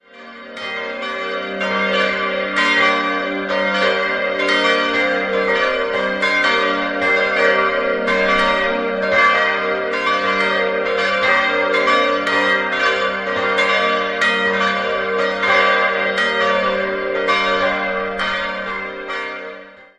Unter Baumeister Giovanni Domenico Barbieri wurde 1756 das Langhaus neu erbaut. 4-stimmiges ausgefülltes G-Moll-Geläute: g'-b'-c''-d'' Die drei größeren Glocken wurden 1964, die kleine 1965 von Friedrich Wilhelm Schilling in Heidelberg gegossen.